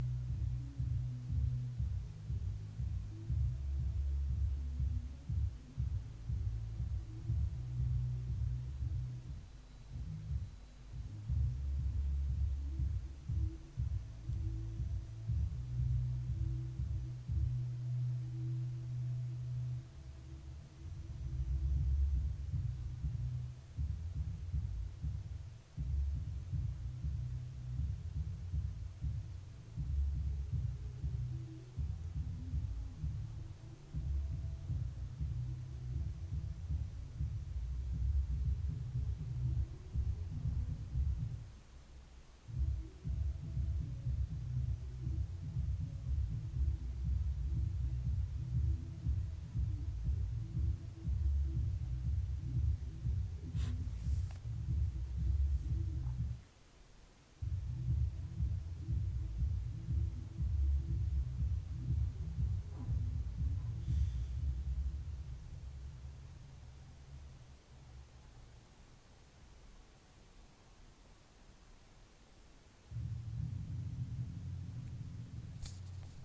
Same livingroom as yesterday but with intrusive beats from downstairs neighbour...